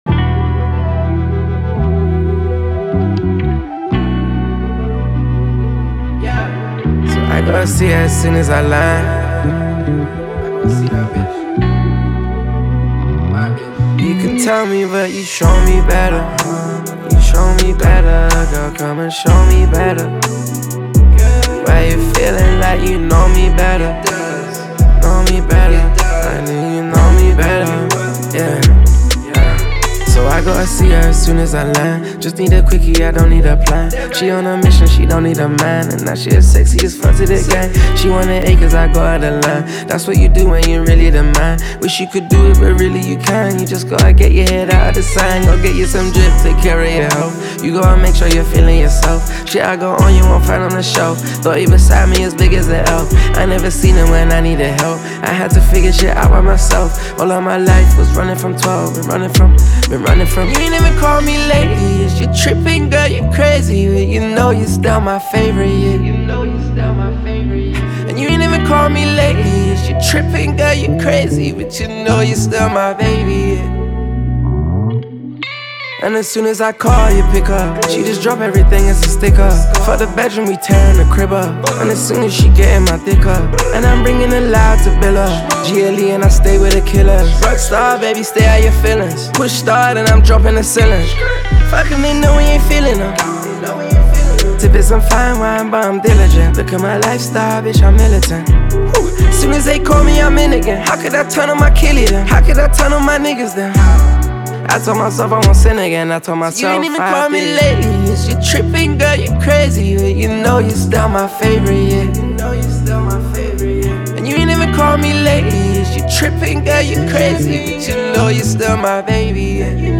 Трек размещён в разделе Рэп и хип-хоп / Зарубежная музыка.